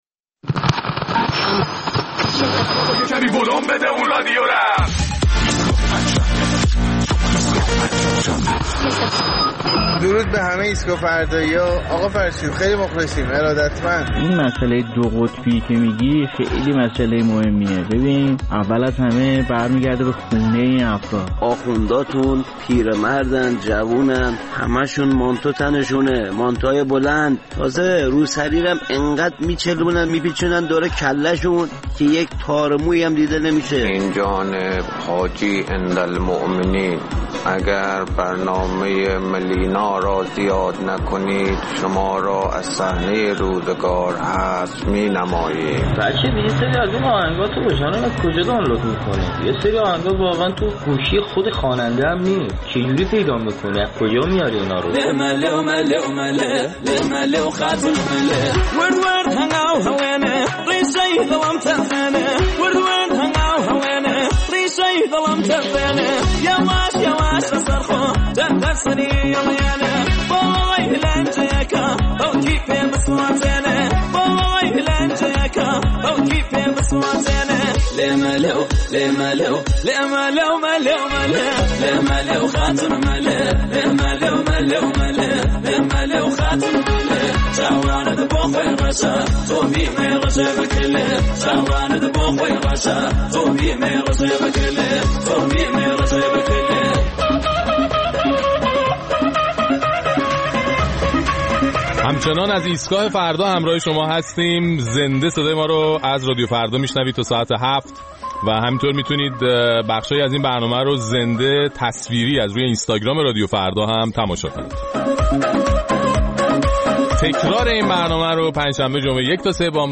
در این برنامه ادامه نظرات شنوندگان ایستگاه فردا را در مورد درگیری‌های اخیر بین نیروها و افراد وابسته به حکومت با مردم و نگرانی‌ها از قطبی شدن جامعه می‌شنویم.